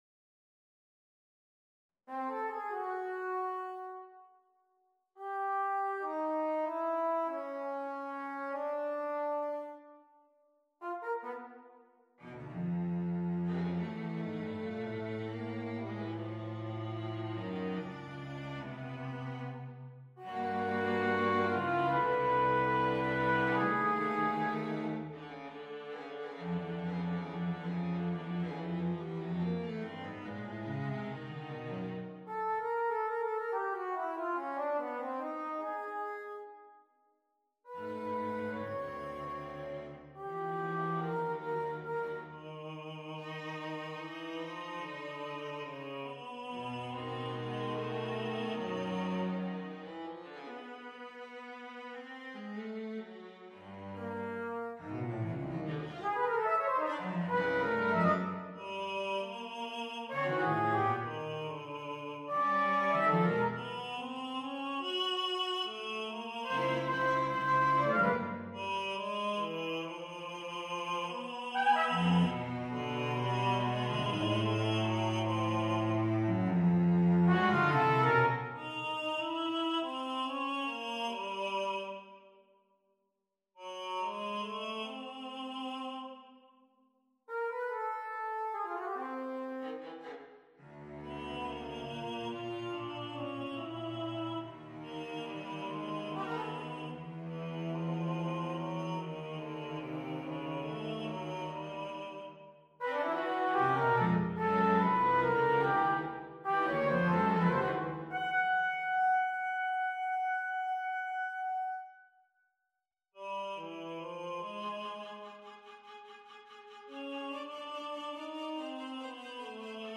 on a purpose-selected tone row
C-A-Ab-Gb-G-Eb-E-Db-D-F-Bb-B